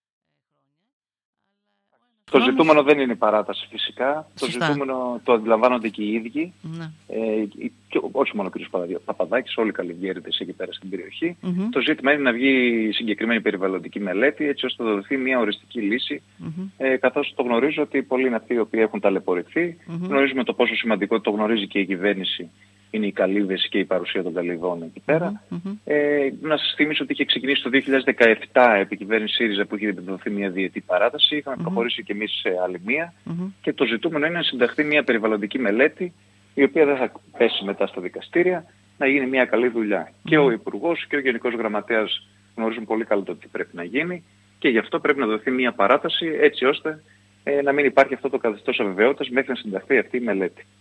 Σε δηλώσεις του στην ΕΡΤ Ορεστιάδας ο κ. Δερμεντζοπουλος τόνισε πως το ζητούμενο δεν είναι η παράταση αυτή καθαυτή αλλά μέσω αυτής να συνταχθεί μια σωστή περιβαλλοντική μελέτη που θα  φέρει το ποθητό αποτέλεσμα για να προσθέσει  πως τόσο ο Υπουργός όσο και ο γενικός γραμματέας  γνωρίζουν πολύ καλά την σημασία της παρουσίας  των καλυβιέρηδων στην περιοχή, την ταλαιπωρία που υφίστανται το τελευταίο διάστημα με την αγωνία να αποτυπώνεται στα πρόσωπα τους για το αν  θα παραμείνουν όρθιες οι καλύβες τους.